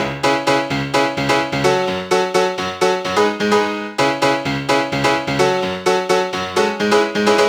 VFH2 128BPM Tron Quarter Melody 2.wav